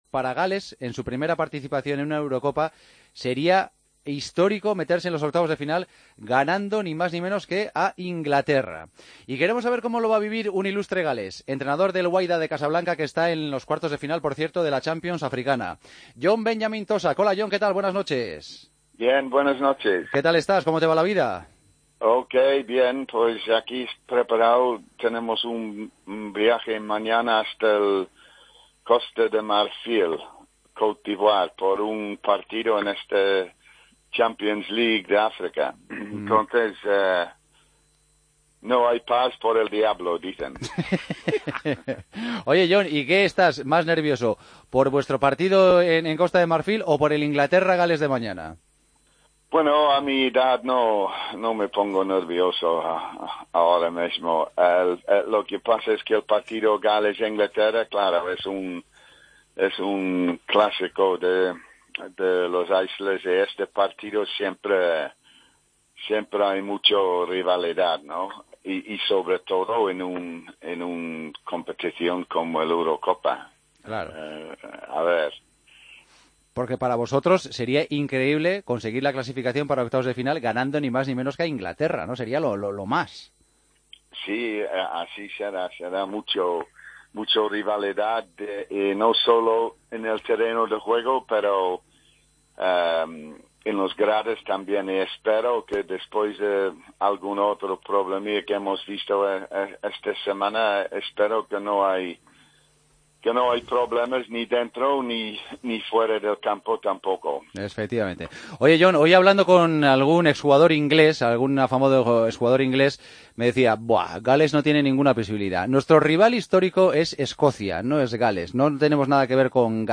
Hablamos con el técnico galés, ante la posibilidad de que Gales haga historia esta Eurocopa, en la previa del partido contra Inglaterra: "El partido entre Inglaterra y Gales siempre genera mucha rivalidad y sobre todo si se juega en la Eurocopa.